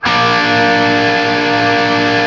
TRIAD A# L-L.wav